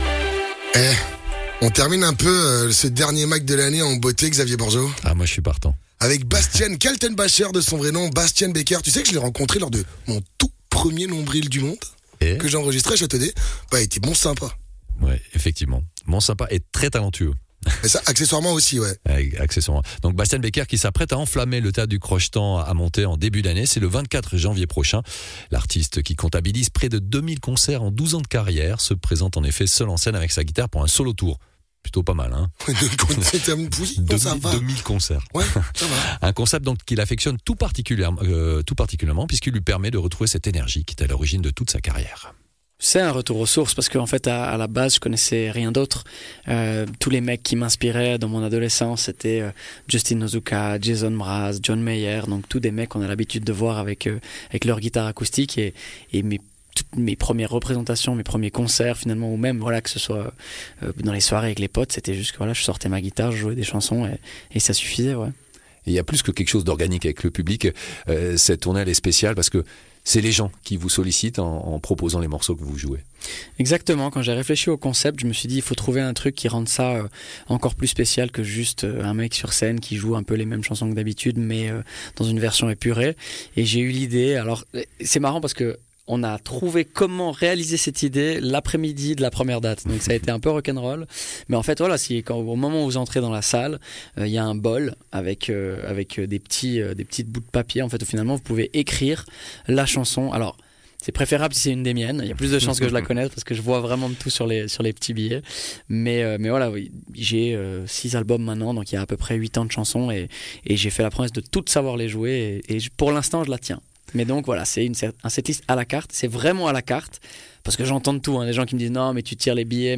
Intervenant(e) : Bastian Baker, musicien, auteur-compositeur et interprète